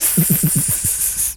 snake_hiss_04.wav